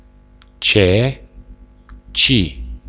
Voiced Sounds of the Romanian Language
Consonants - Speaker #6
ce ci